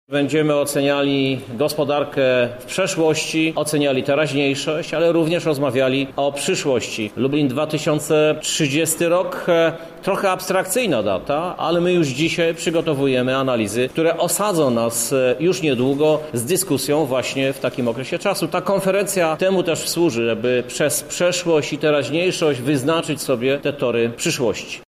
Prezydent Krzysztof Żuk, który będzie jednym z prelegentów, przekonuje, że konferencja jest bardzo ważna dla przyszłości miasta.